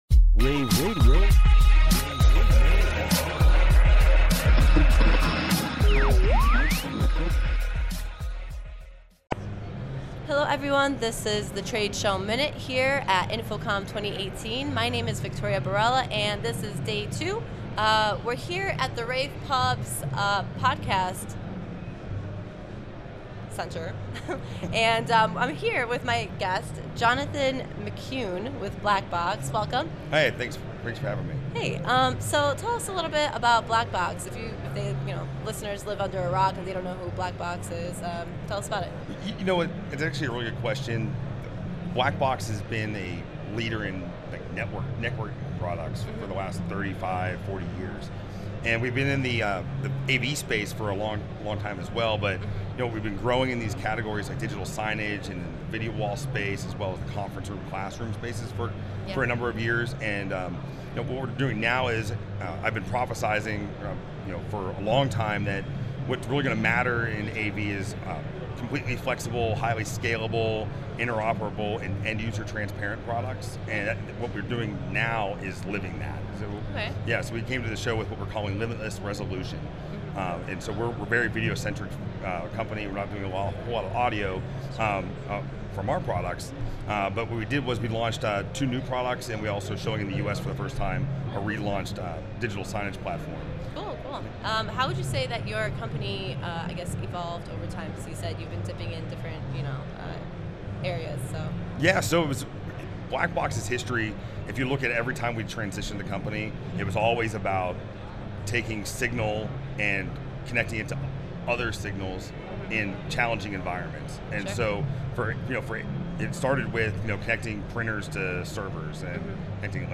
InfoComm Radio